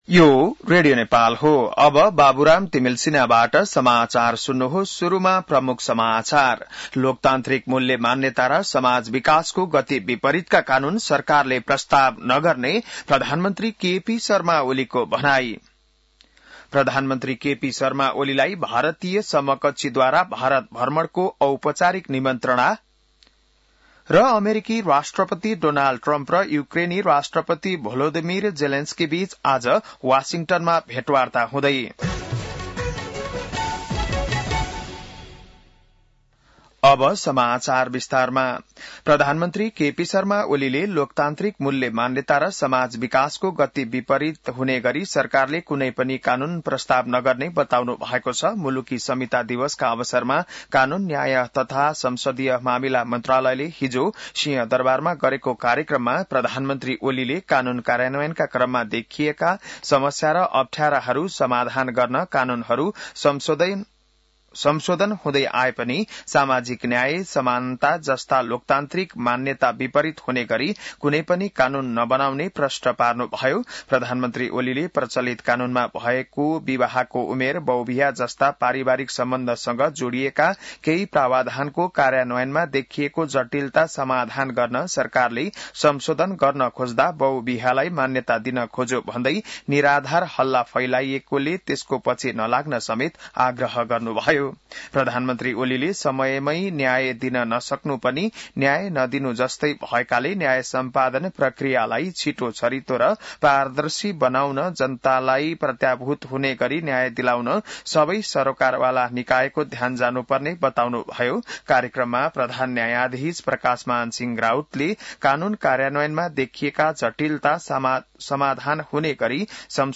बिहान ९ बजेको नेपाली समाचार : २ भदौ , २०८२